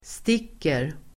Uttal: [st'ik:er]